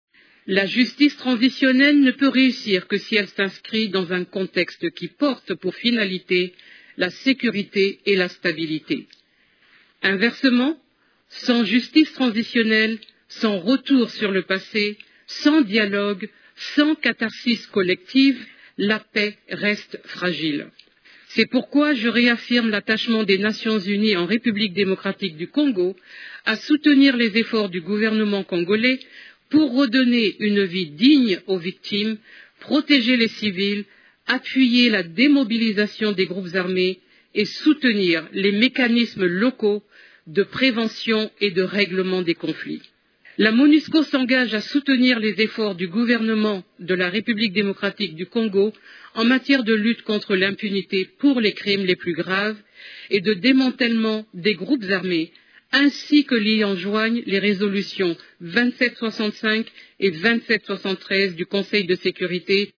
Elle a fait cette déclaration lors de son intervention au cours de de la conférence de haut niveau sur la reconnaissance des génocides commis en RDC au cours des trente dernières années, lundi 8 septembre à Genève (Suisse).